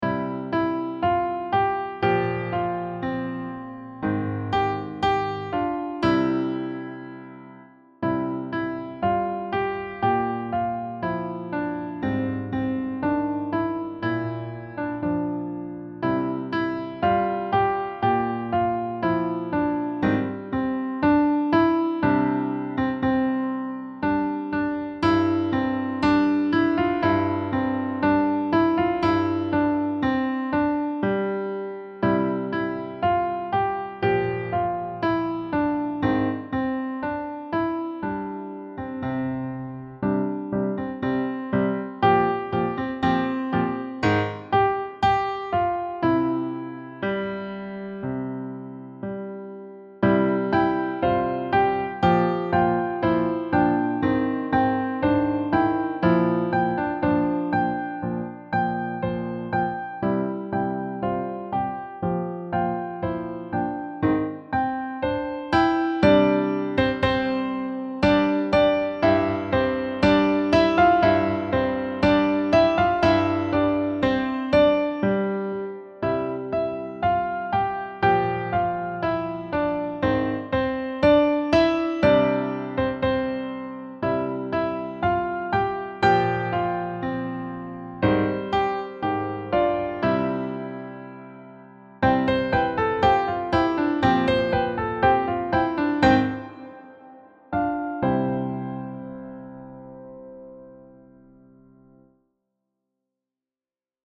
Key: C Major
Time Signature: 4/4